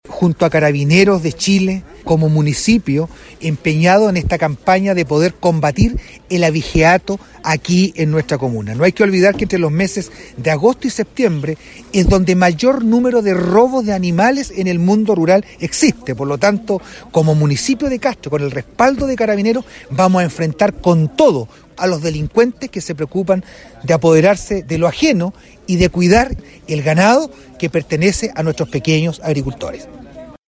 El alcalde de Castro, Juan Eduardo Vera señaló que en los meses de Agosto y Septiembre se disparan las cifras del delito de abigeato en la zona.